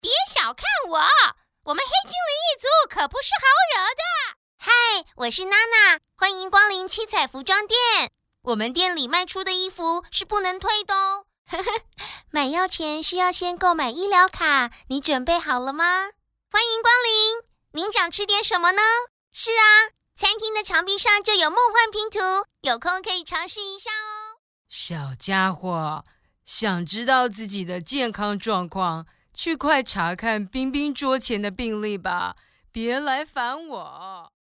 Young and energetic Mandarin Chinese and Taiwanese voice over talent.
Sprechprobe: Industrie (Muttersprache):